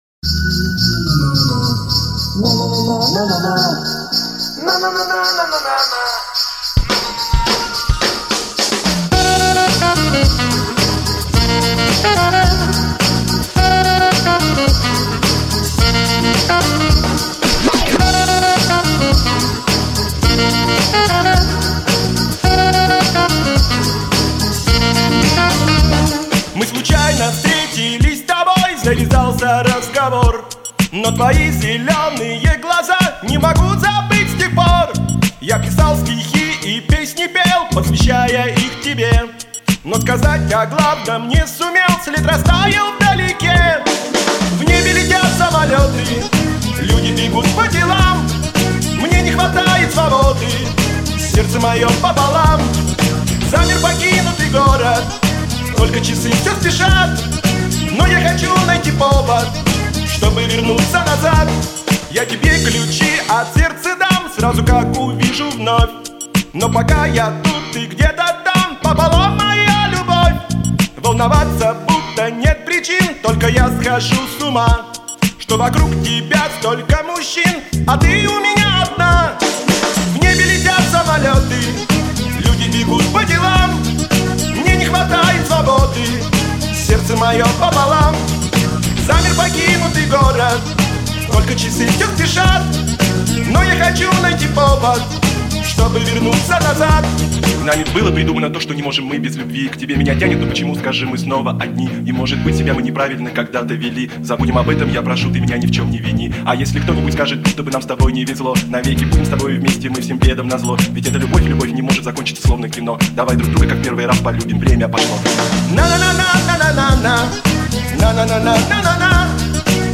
:)))) Вообще очень хороший и приятный голос!